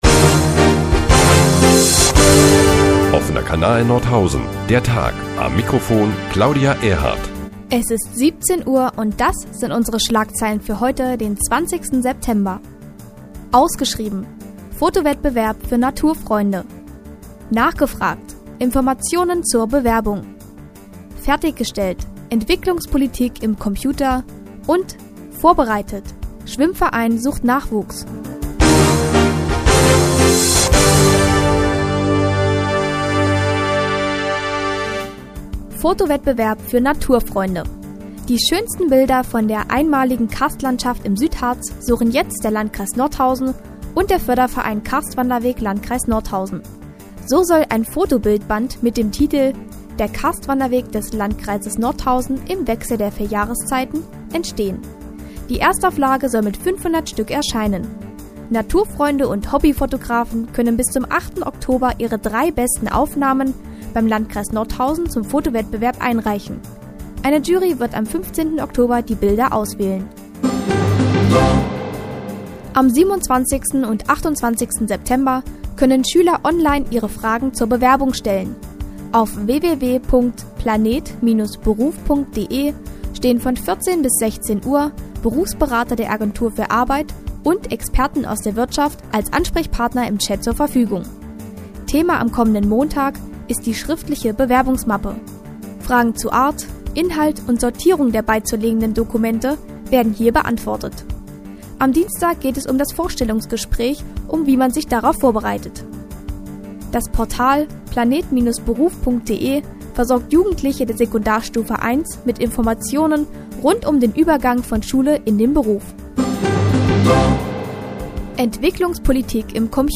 20.09.2010, 16:10 Uhr : Seit Jahren kooperieren die nnz und der Offene Kanal Nordhausen. Die tägliche Nachrichtensendung des OKN ist auch in der nnz zu hören.